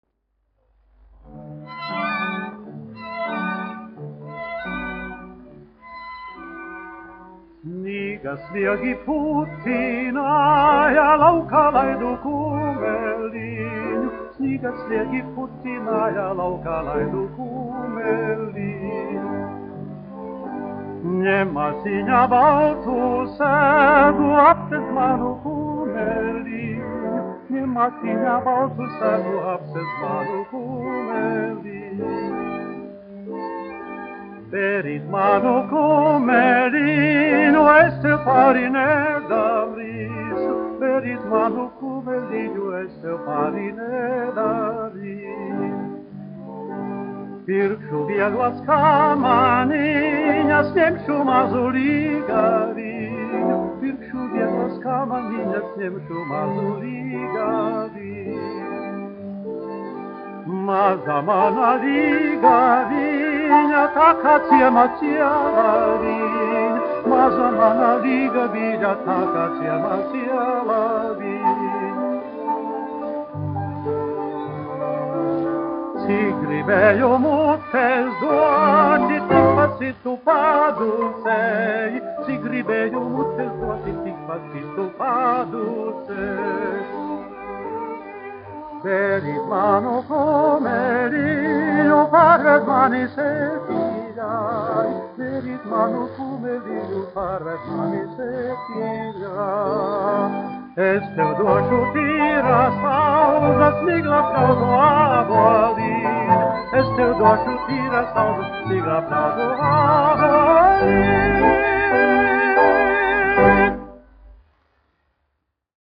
Barisons, Pēteris, 1904-1947, aranžētājs
Otto Karls, aranžētājs
1 skpl. : analogs, 78 apgr/min, mono ; 25 cm
Latviešu tautasdziesmas
Skaņuplate